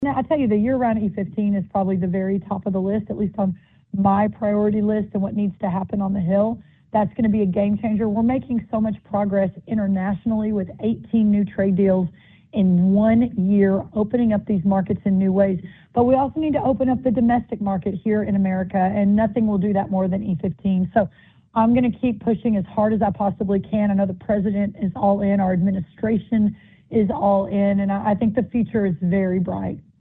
In an interview with the Iowa Agribusiness Radio Network, U.S. Agriculture Secretary Brooke Rollins said efforts are underway to address rising input costs while also working to strengthen demand for U.S. commodities.